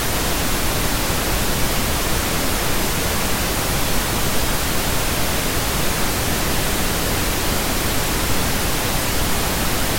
Our dB simulator provides pink noise at different decibel levels.
Pink-Noise-6-dB.mp3